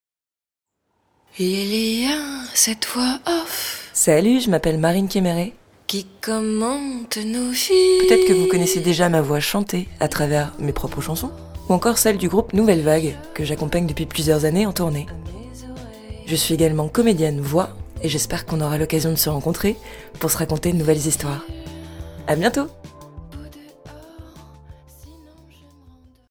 Comédienne voix off